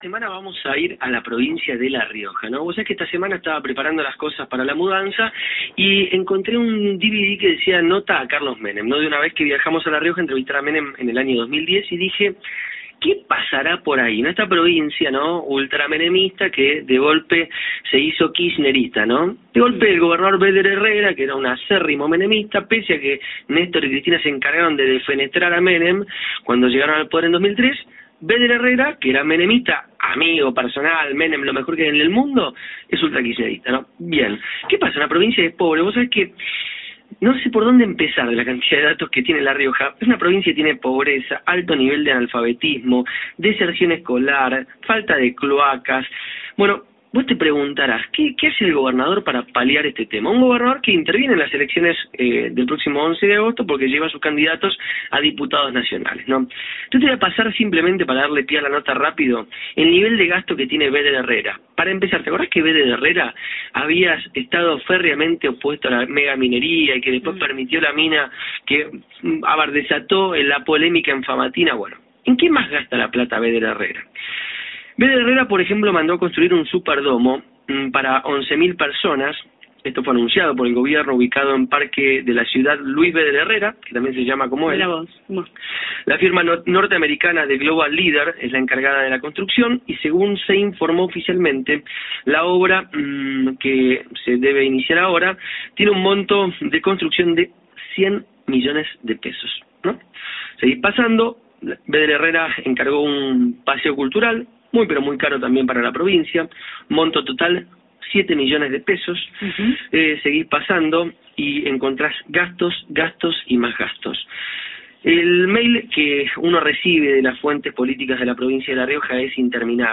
El informe de Radio Mitre